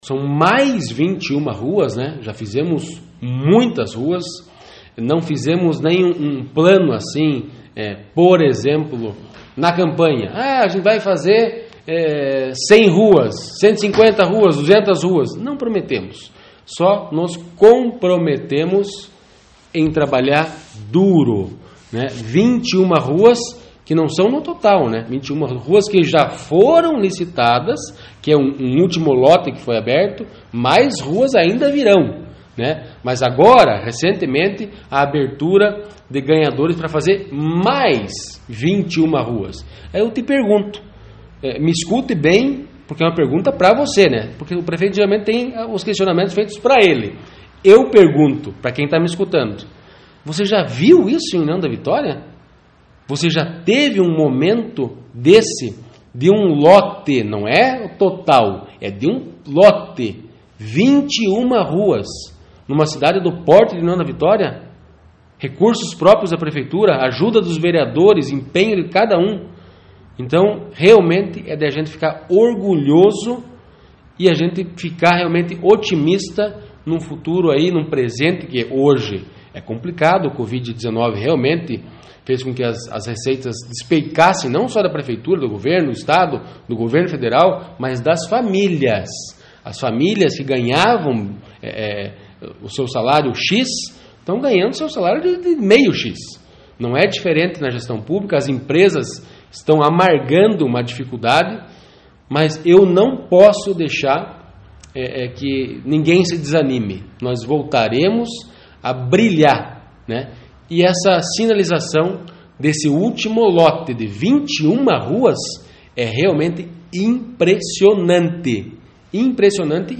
PREFEITO-SANTIN-ROVEDA-FALA-DA-LICITAÇÃO-DE-MAIS-21-RUAS-PARA-SER-PAVIMENTADA.mp3